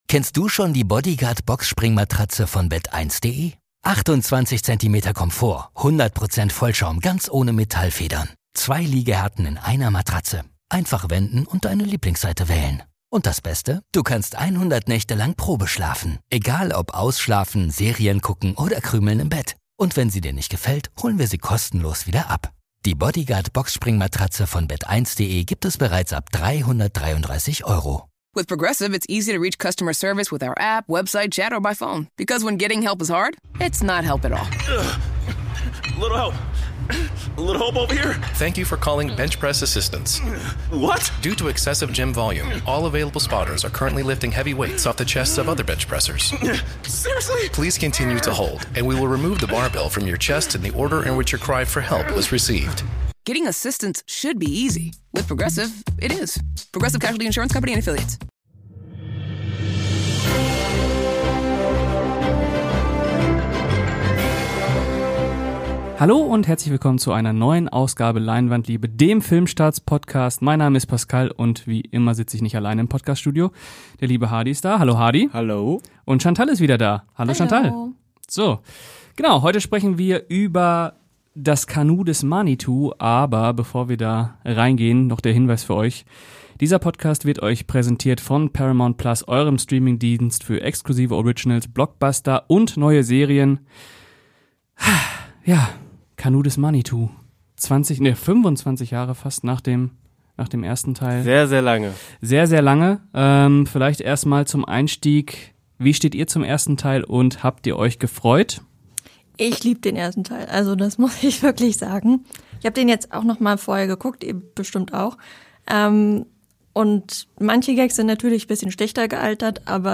Ohne Skript, aber mit Liebe und Fachwissen.